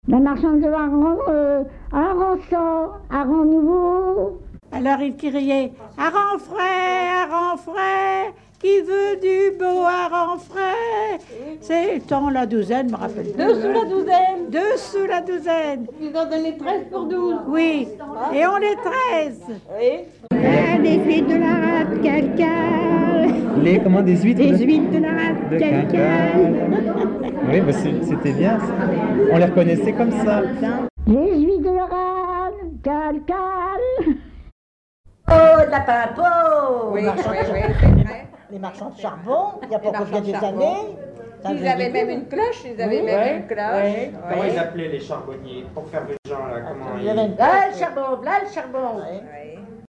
Suite de cris de rue - Vente d'animaux
Genre brève
Catégorie Pièce musicale inédite